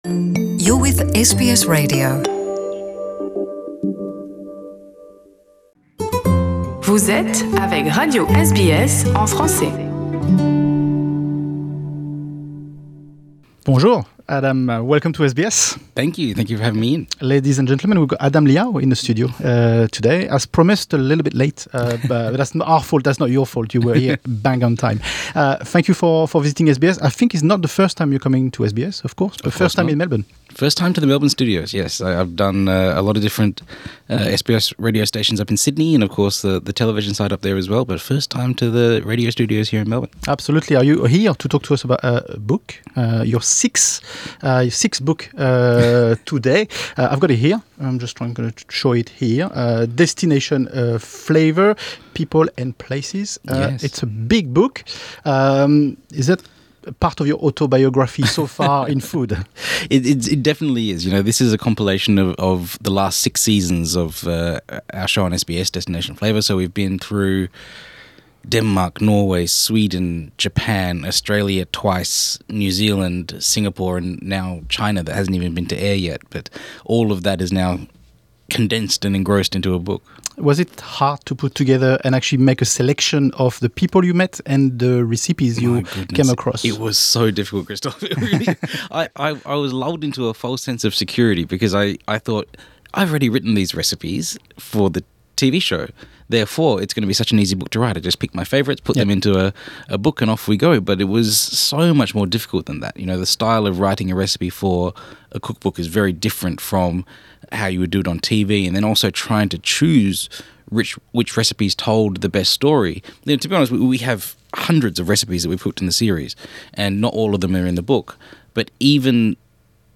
Destination Flavour host Adam Liaw has just launched a book celebrating the food, people and places he encountered while filming the TV show. He came to visit us in our studio to have a chat.